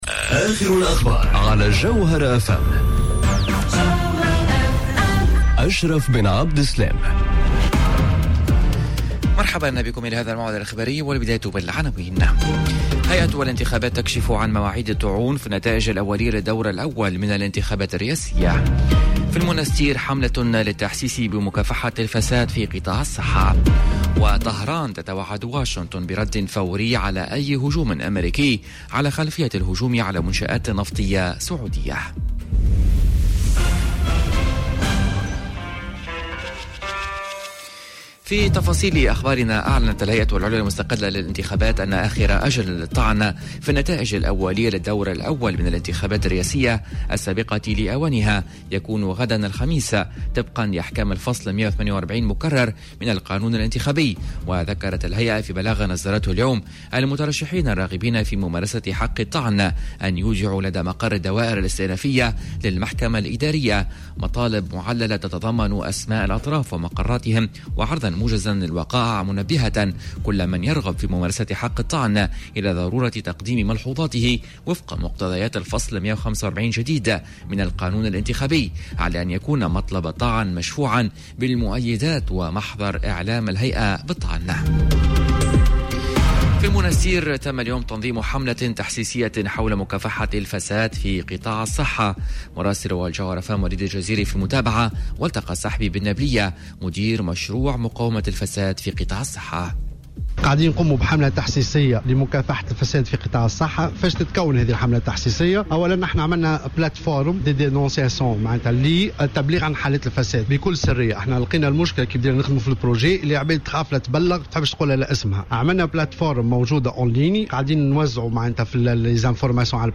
نشرة أخبار منتصف النهار ليوم الإربعاء 18 سبتمبر 2019